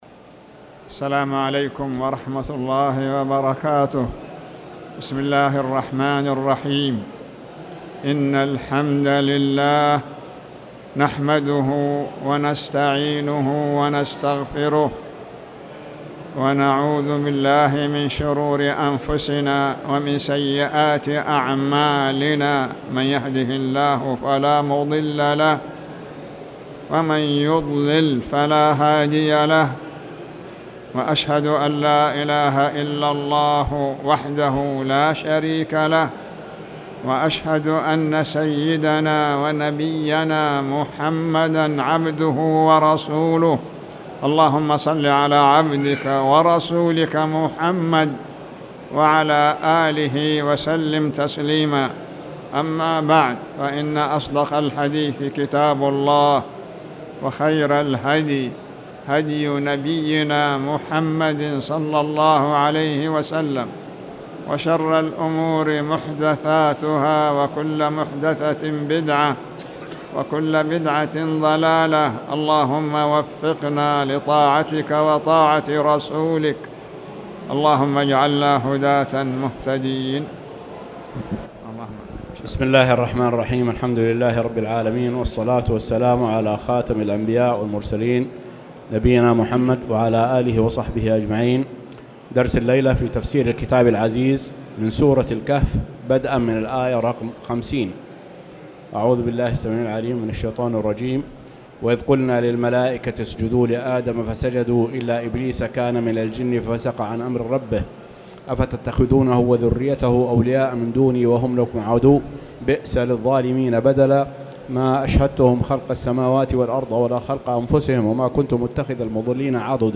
تاريخ النشر ١٨ محرم ١٤٣٨ هـ المكان: المسجد الحرام الشيخ